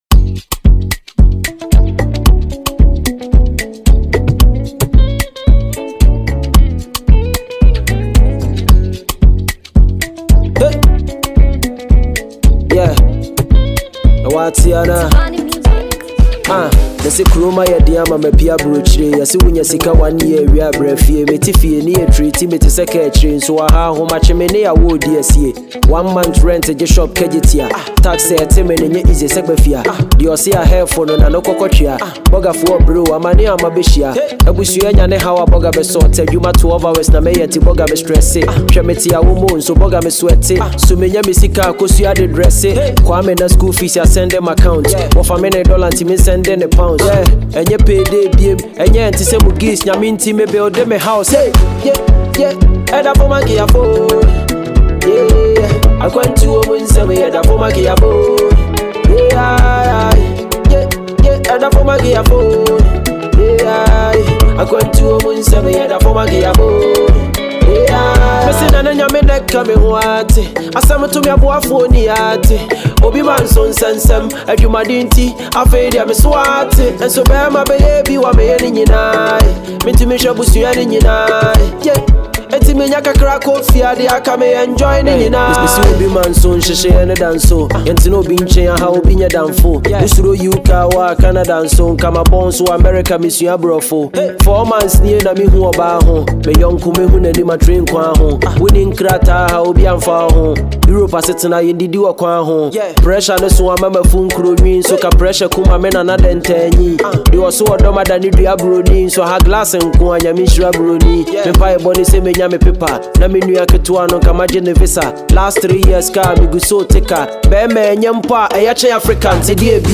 an award-winning Ghanaian rapper